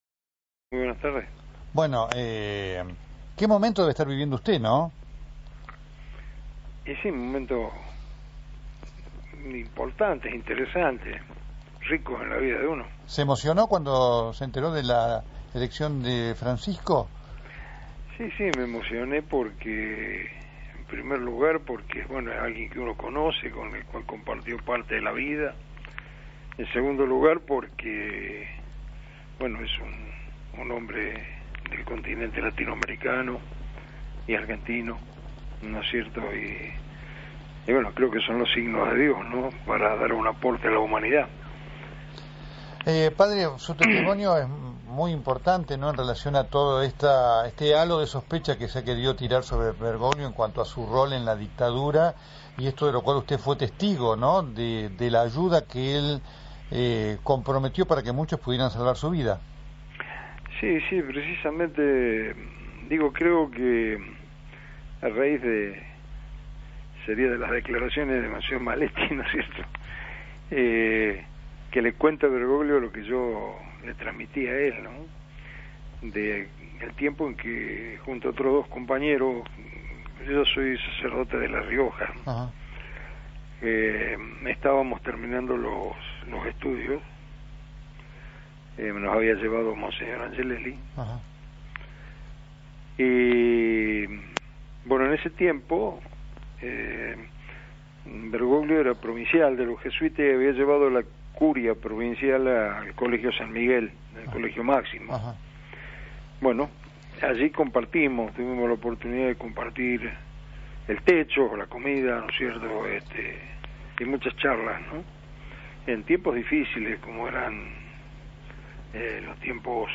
por Radio Continental